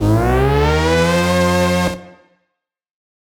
Index of /musicradar/future-rave-samples/Siren-Horn Type Hits/Ramp Up
FR_SirHornB[up]-G.wav